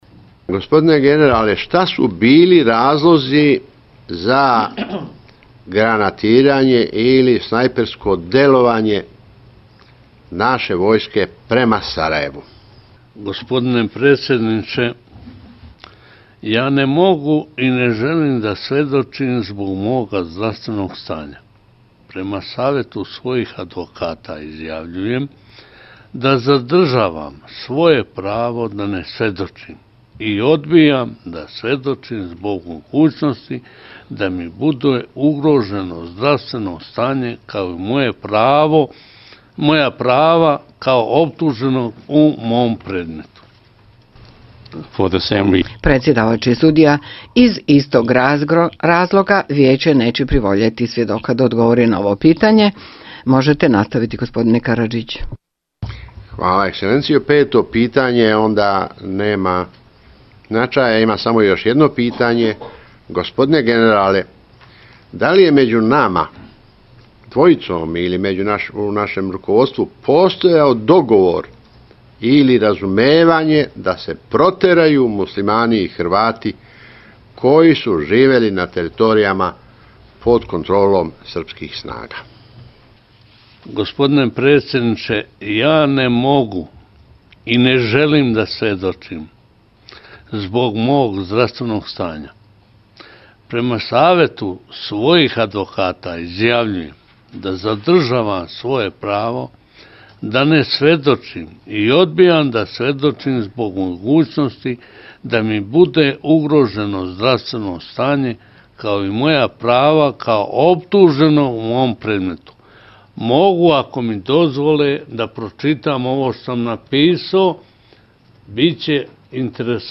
Ratko Mladić, koji je optužen za iste zločine iz prve polovice '90-tih godina kao i Radovan Karadžić na čijem se suđenju pojavio kao svjedok, odbio je odgovarati na pitanja uz vikanje i uvrede upućene Haškom sudu